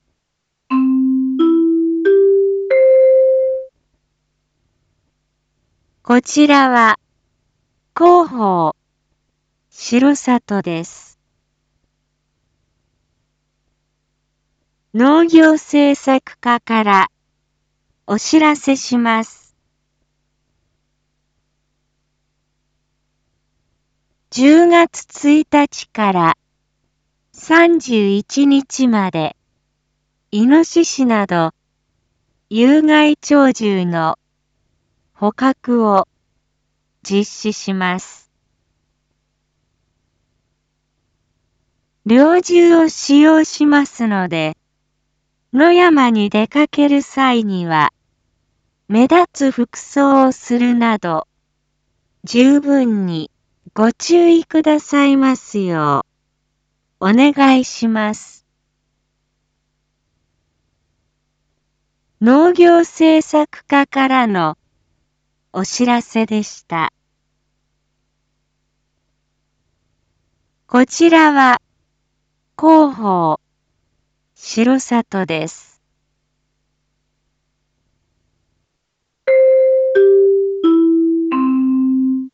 一般放送情報
Back Home 一般放送情報 音声放送 再生 一般放送情報 登録日時：2022-10-07 19:01:24 タイトル：R4.10.7 19時放送分 インフォメーション：こちらは、広報しろさとです。